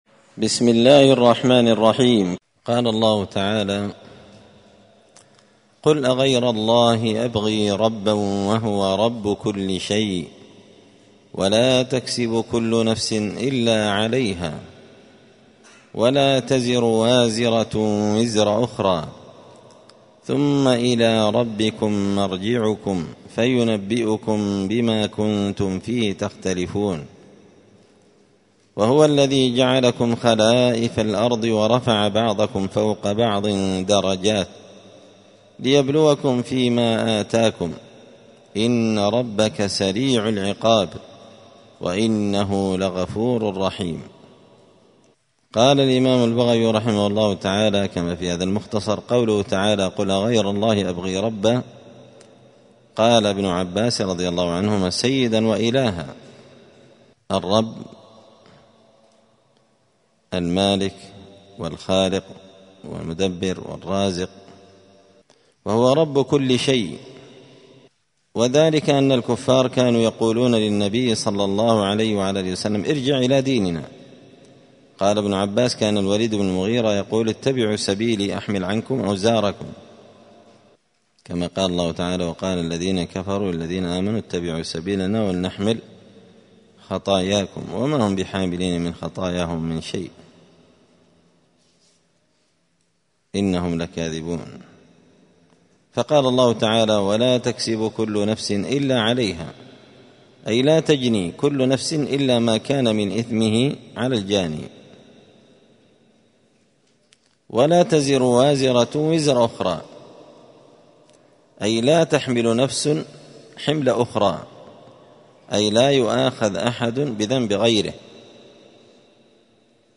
📌الدروس اليومية
دار الحديث السلفية بمسجد الفرقان بقشن المهرة اليمن